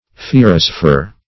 Search Result for " fierasfer" : The Collaborative International Dictionary of English v.0.48: Fierasfer \Fi`e*ras"fer\, n. [NL.]